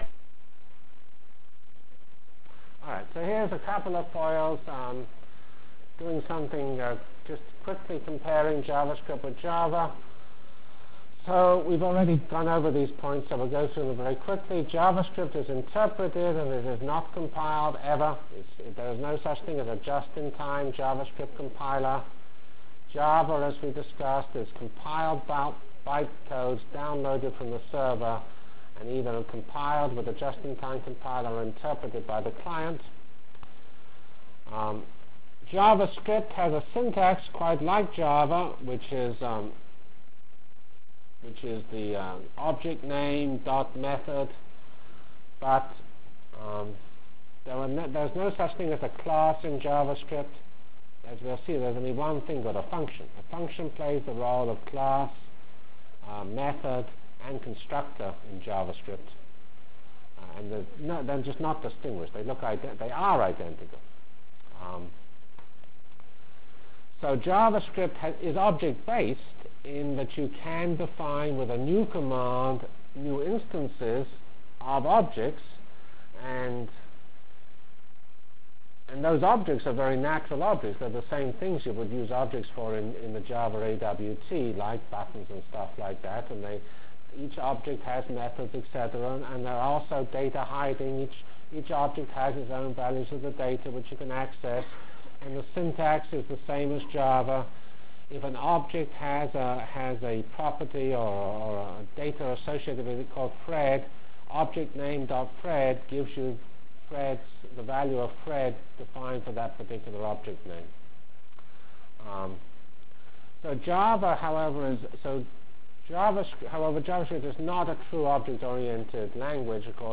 From Feb 12 Delivered Lecture for Course CPS616 -- Basic JavaScript Functionalities and Examples CPS616 spring 1997 -- Feb 12 1997.